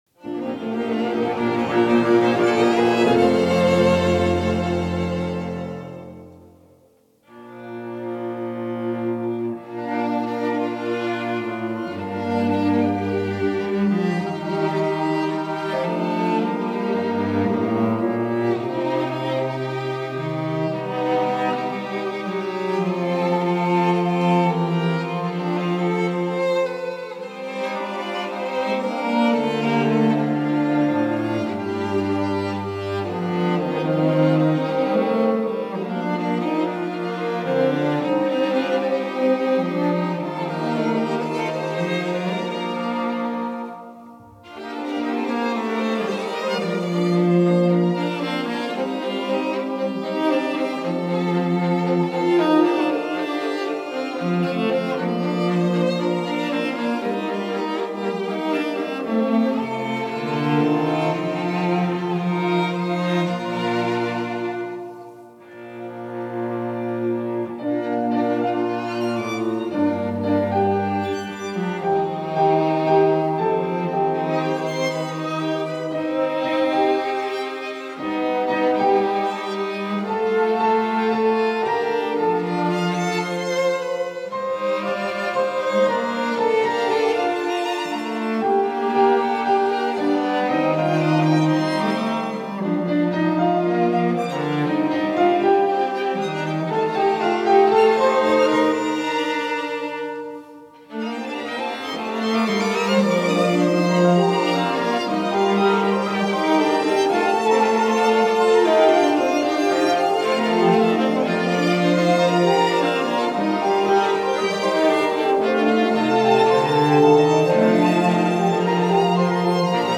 ピアノ五重奏団